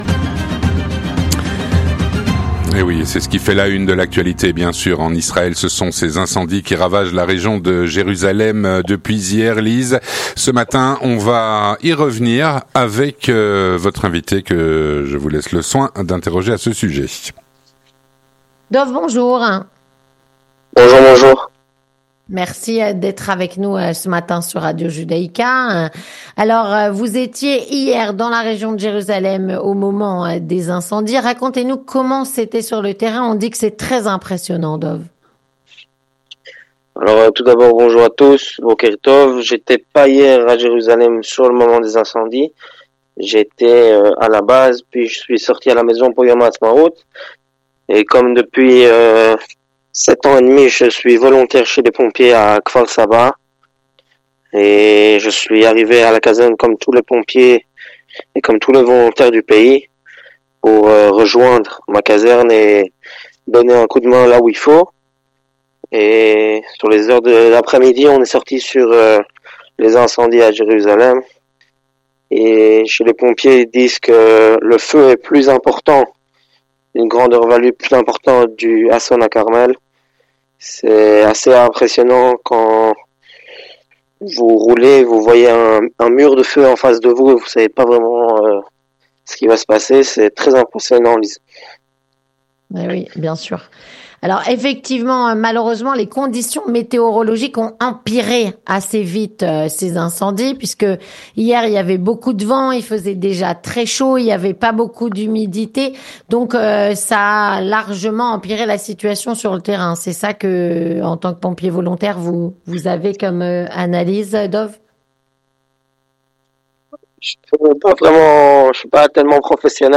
Édition Spéciale - Des incendies, probablement d'origine terroriste, ravagent la région de Jérusalem.